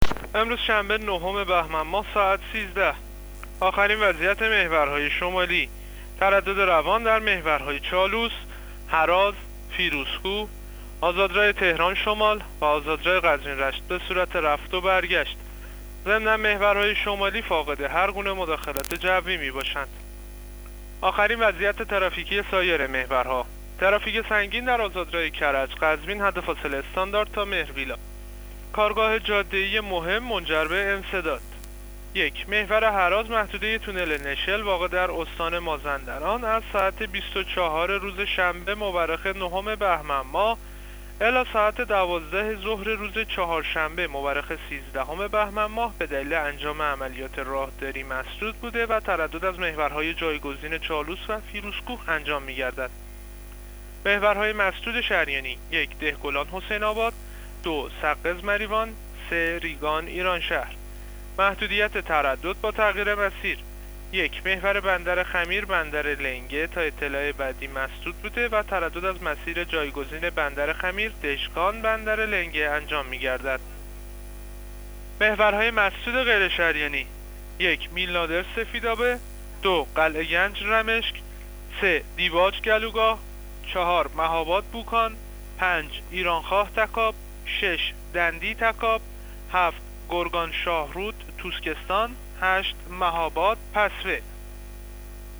گزارش رادیو اینترنتی از آخرین وضعیت ترافیکی جاده‌ها ساعت ۱۳ نهم بهمن؛